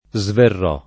svenire